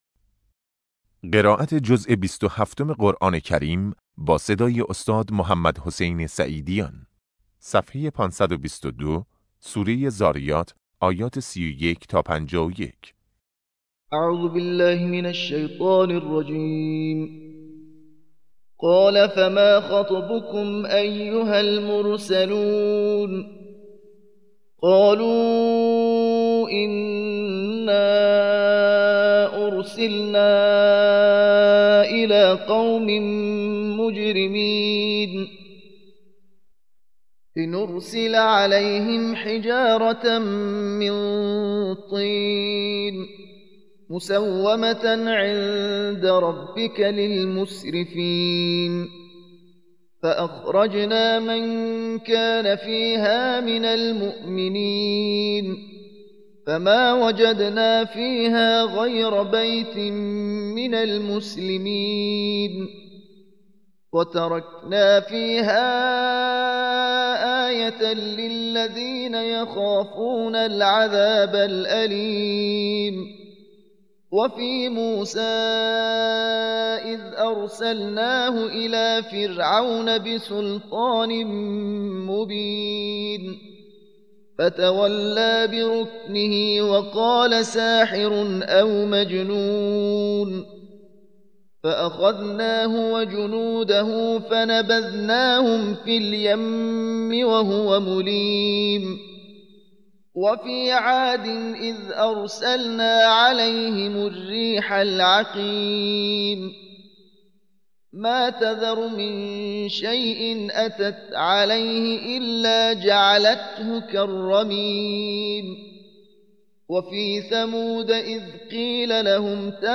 قرائت درس پنجم جلسه اول و دوم